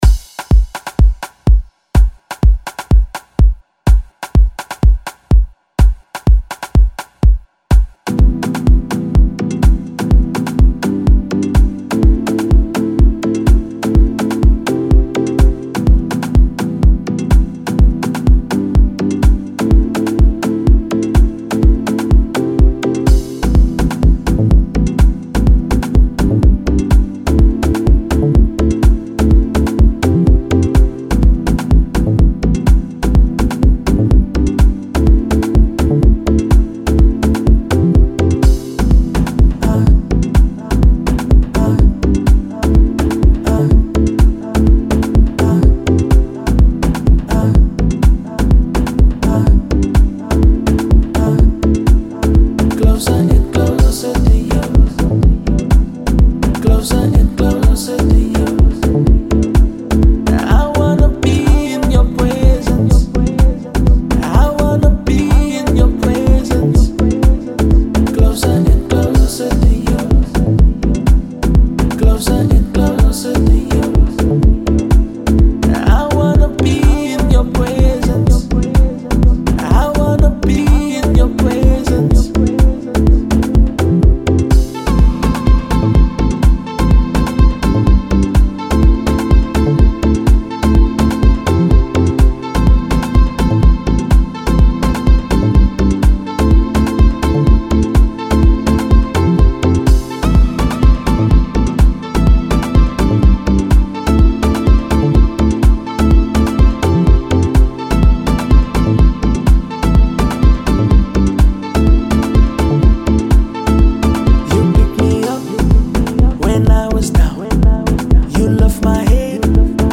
deep house track with a groovy bassline and a soft piano